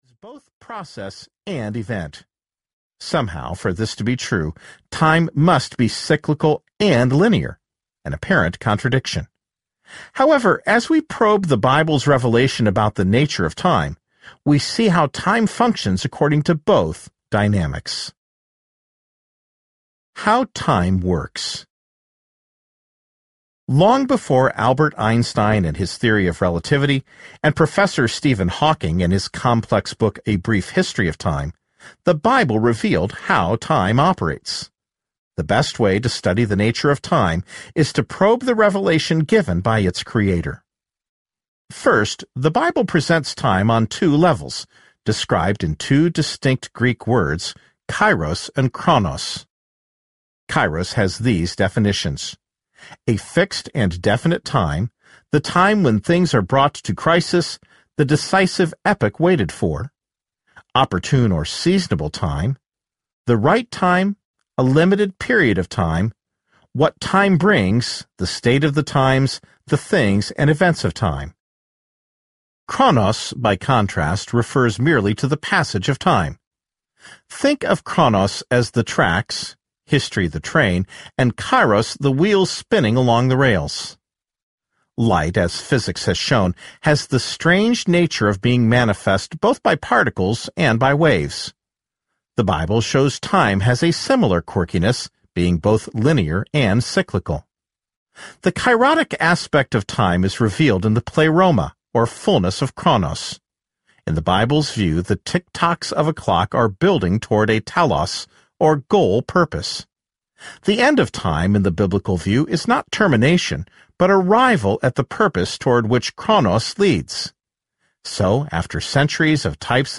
Globequake Audiobook
Narrator
8 Hrs. – Unabridged